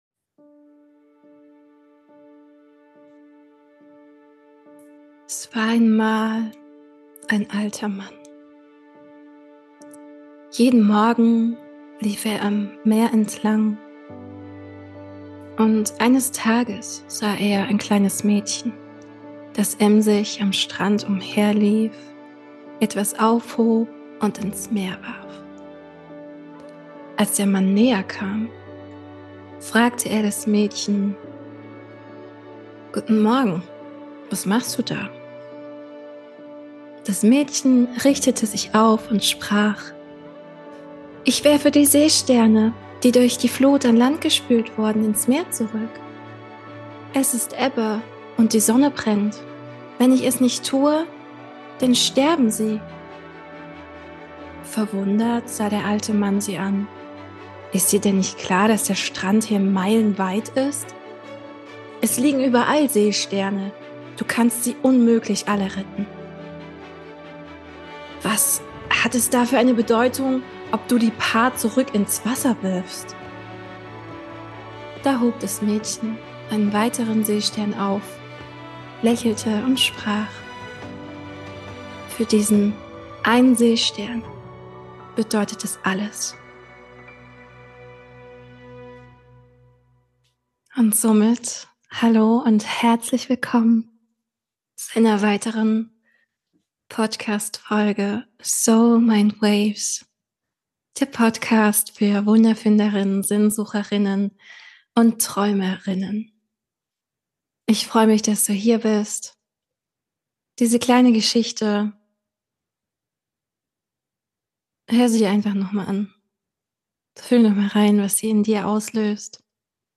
Meditation -Time: Aufgrund dieses grauen Wetters, nehme ich dich zum Energie tanken auf eine Reise zur Sonne mit.